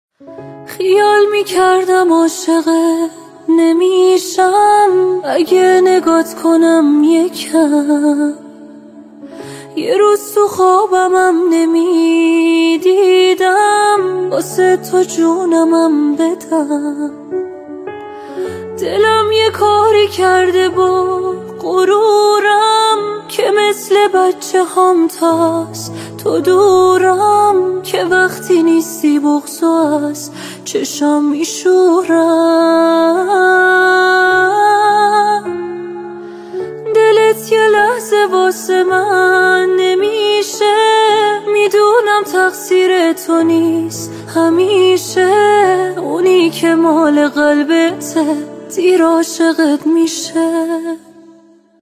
برچسب‌ها: اهنگ های ترند اینستاگرام ترند اهنگ با صدای زن دیدگاه‌ها (اولین دیدگاه را بنویسید) برای ارسال دیدگاه وارد شوید.